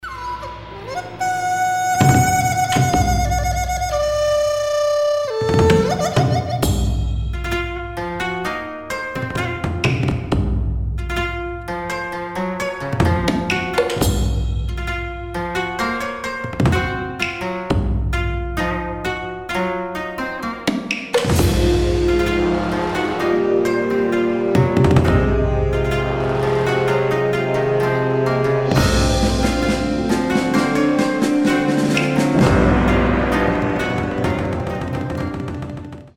• Качество: 320, Stereo
без слов
арфа
гимны
Гимн японской армии, или японский военный гимн